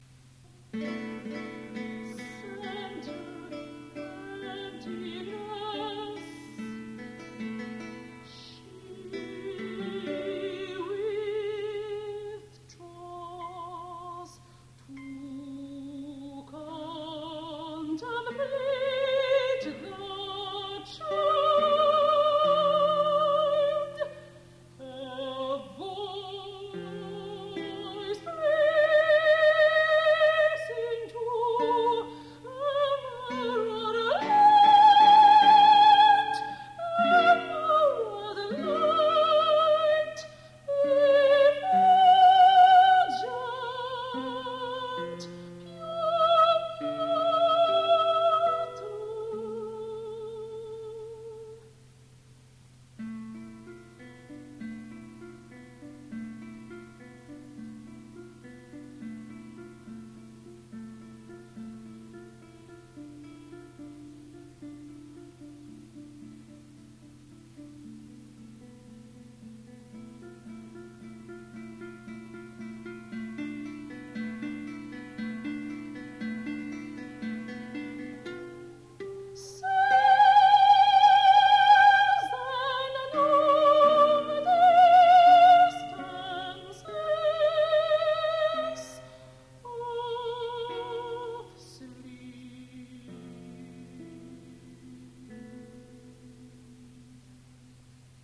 soprano, guitar        Denton, Texas  1983